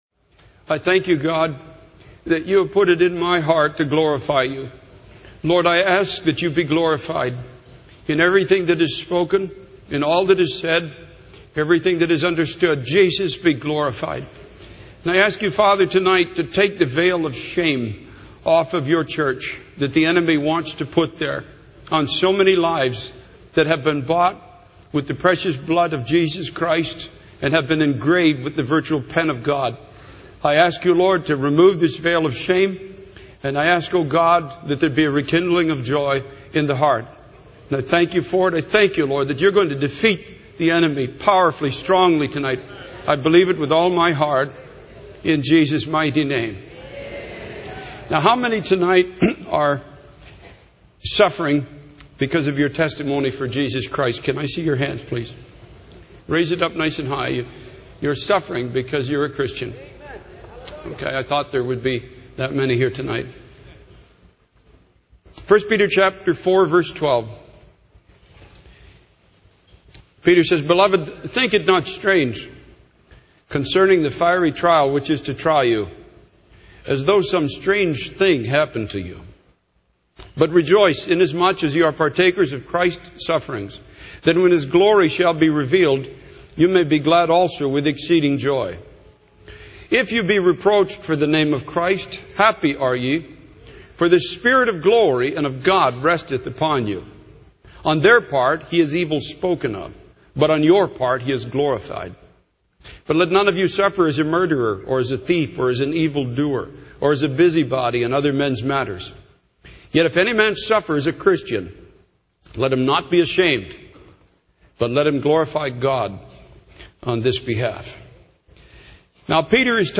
In this sermon, the speaker shares his personal experience of facing opposition and persecution for his faith in Christ.